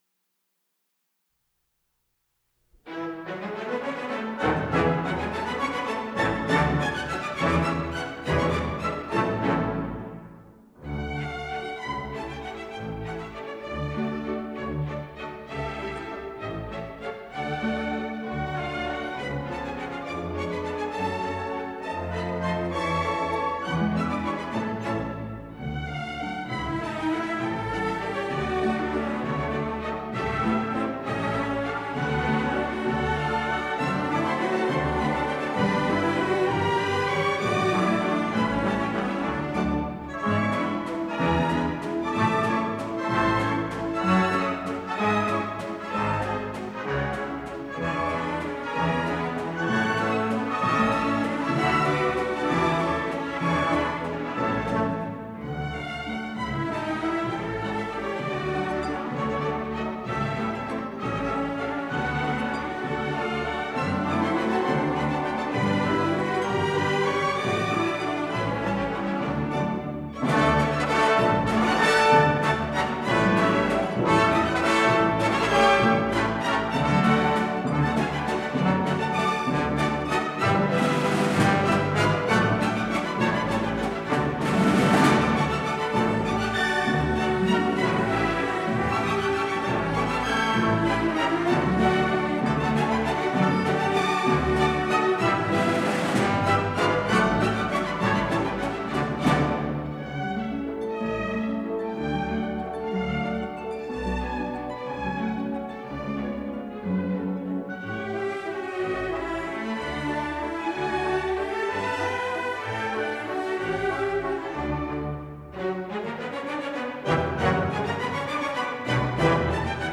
Kingsway Hall